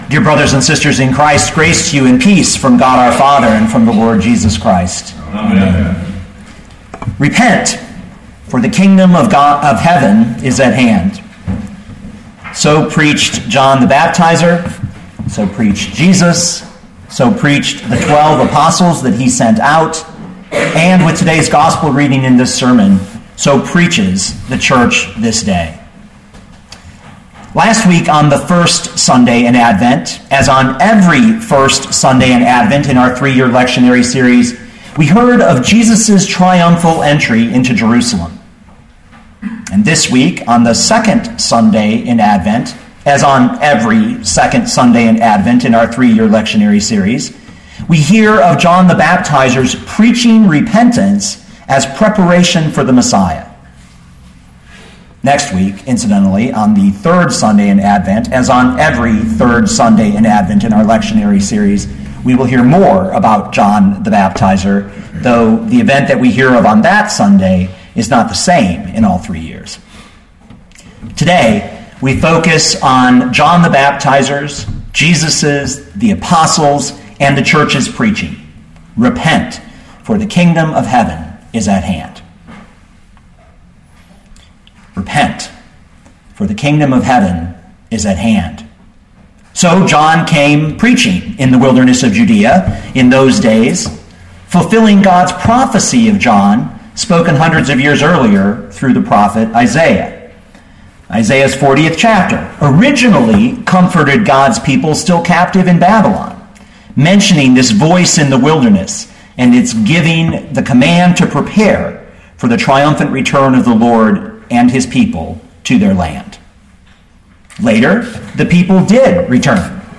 2013 Matthew 3:1-12 Listen to the sermon with the player below, or, download the audio.